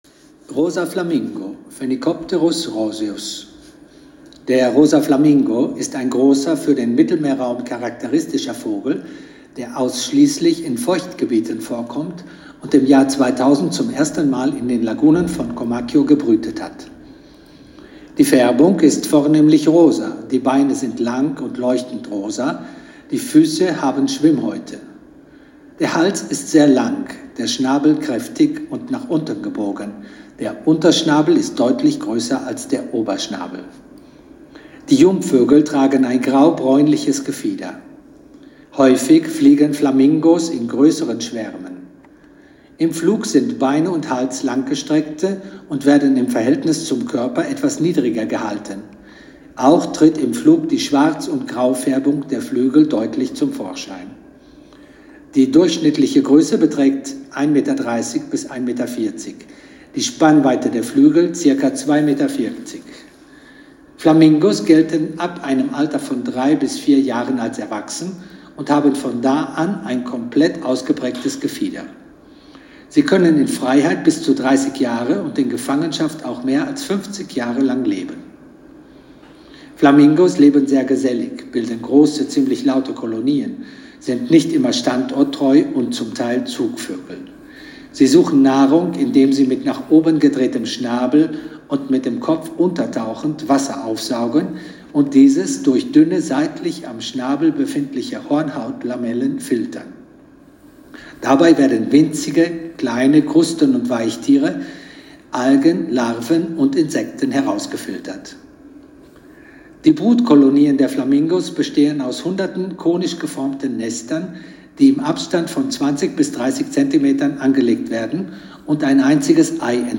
Audioguides auf Deutsch - Salina di Comacchio